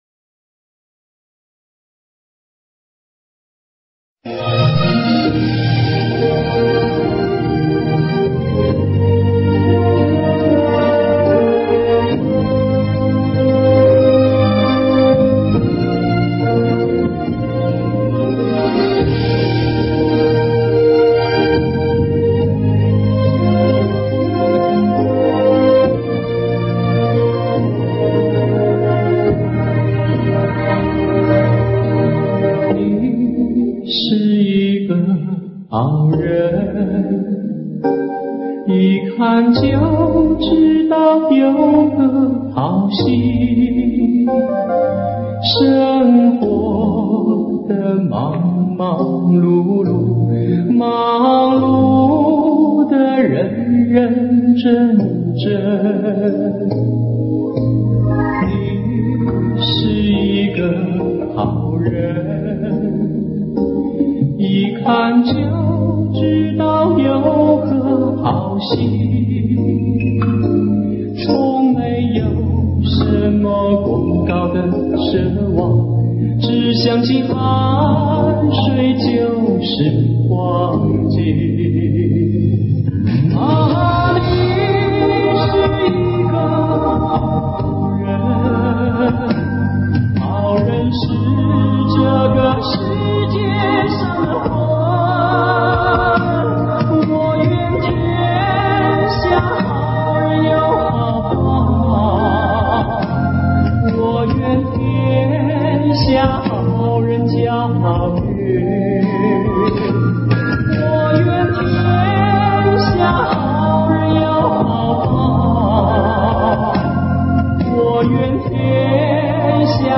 本音频音质差些